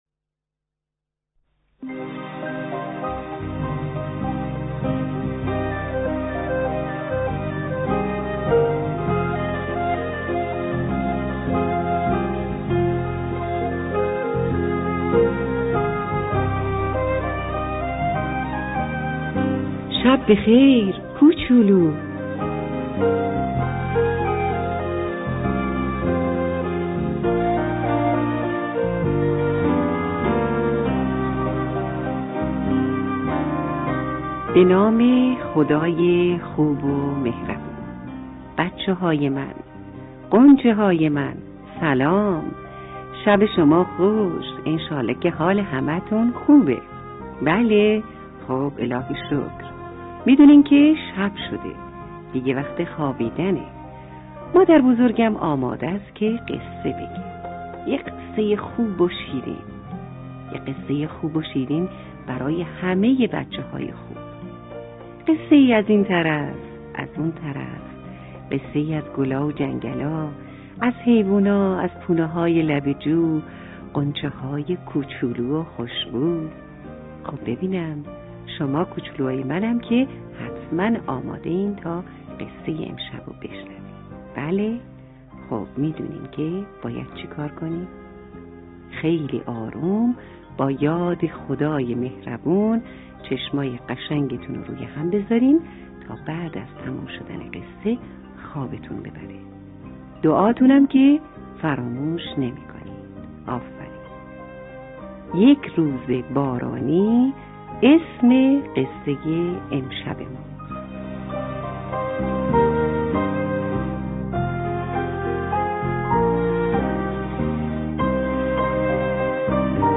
داستان کوتاه لالایی؛ یک روز بارانی